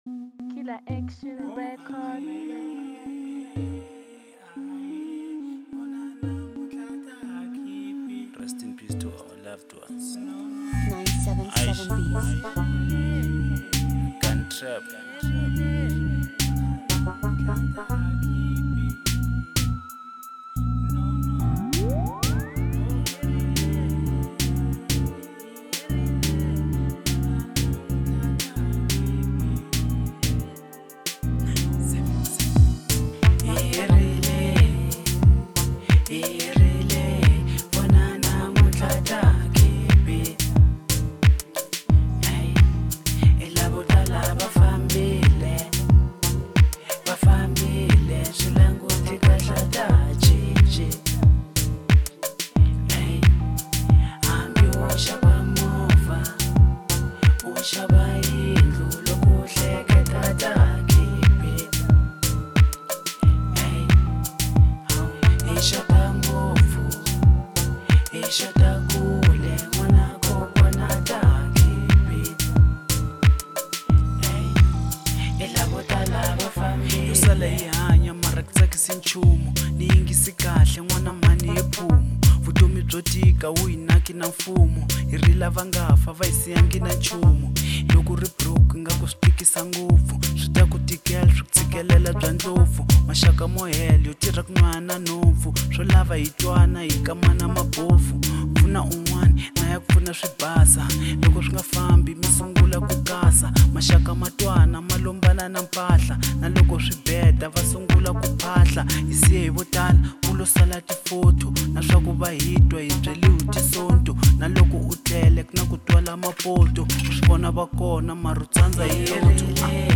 Genre : African Disco